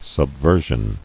[sub·ver·sion]